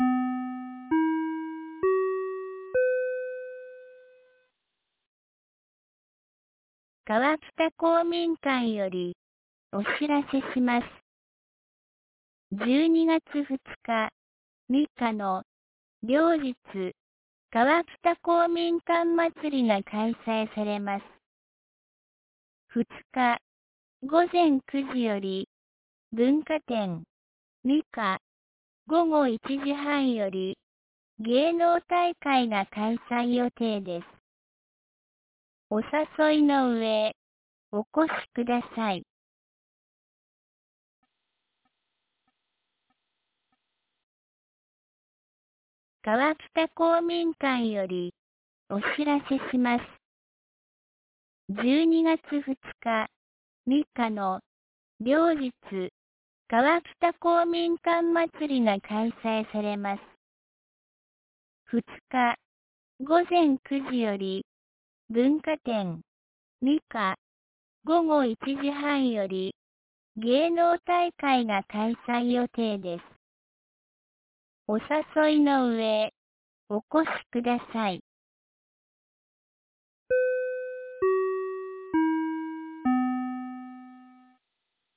2023年12月01日 12時11分に、安芸市より川北へ放送がありました。